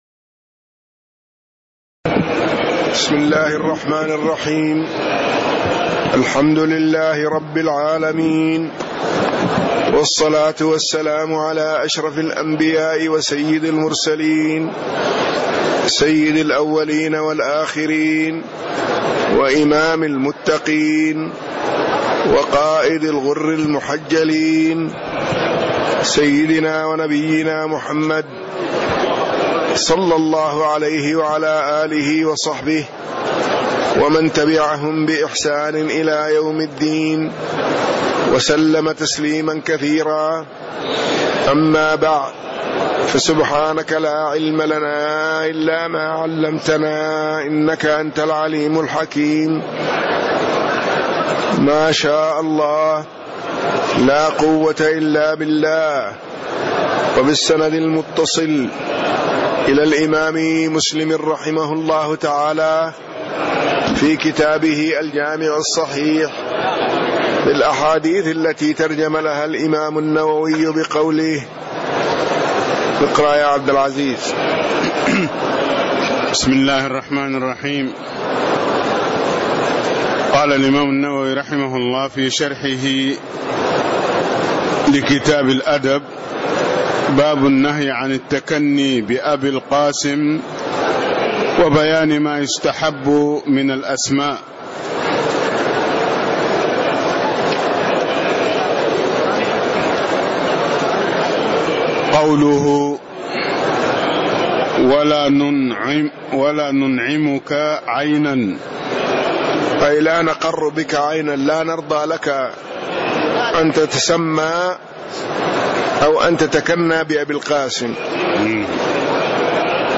تاريخ النشر ١٥ ذو القعدة ١٤٣٦ هـ المكان: المسجد النبوي الشيخ